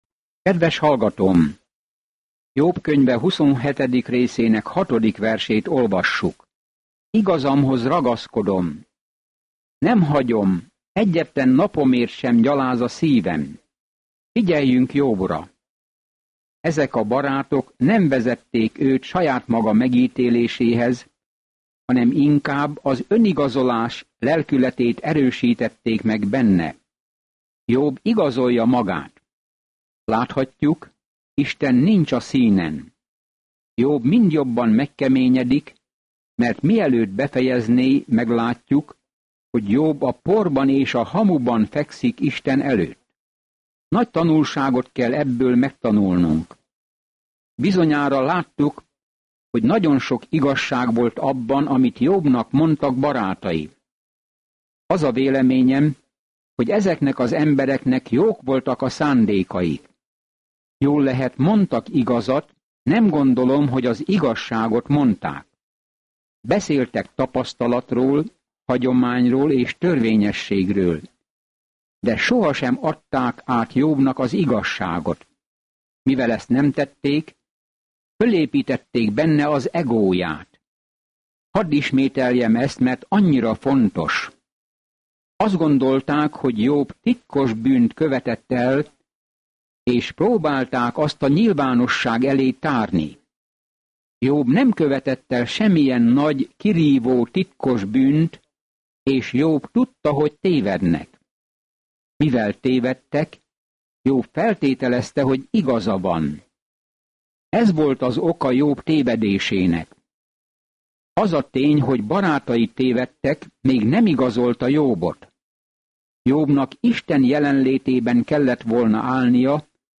Szentírás Jób 27:6-23 Jób 28 Jób 29:1-10 Nap 15 Olvasóterv elkezdése Nap 17 A tervről Ebben az ég és föld drámában találkozunk Jóbbal, egy jó emberrel, akit Isten megengedte a Sátánnak, hogy megtámadja; mindenkinek annyi kérdése van azzal kapcsolatban, hogy miért történnek rossz dolgok. Naponta utazz Jóbon, miközben hallgatod a hangos tanulmányt, és olvasol válogatott verseket Isten szavából.